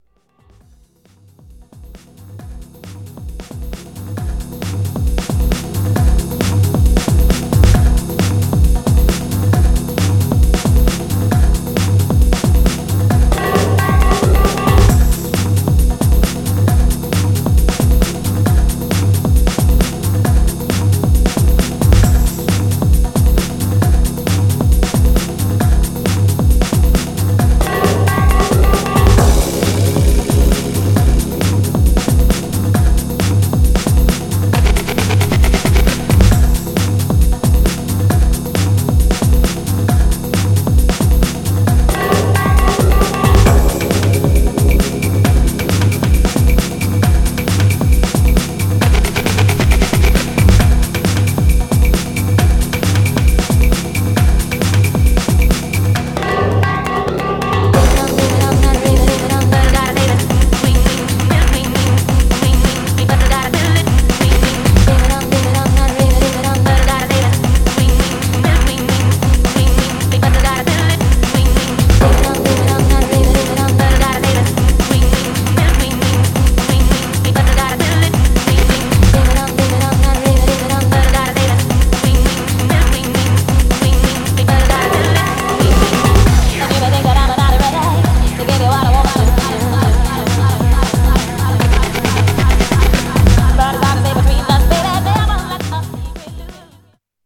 Styl: Progressive, House, Breaks/Breakbeat